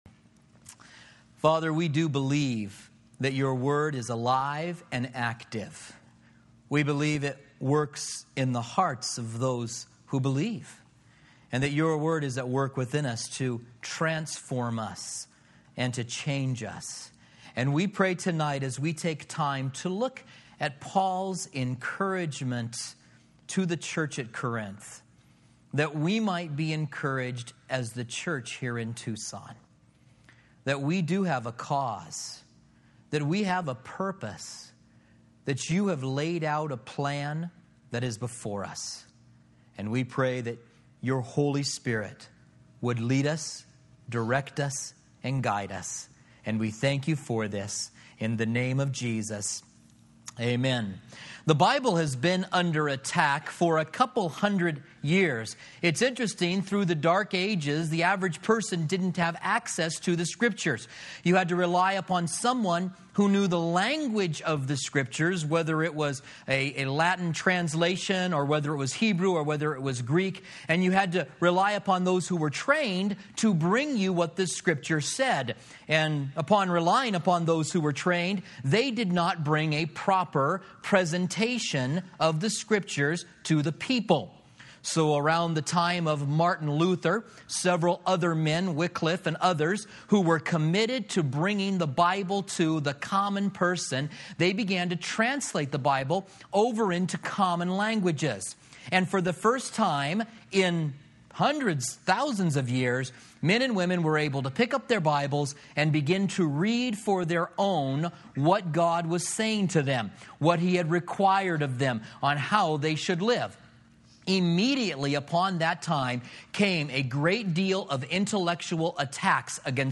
Commentary on 2 Corinthians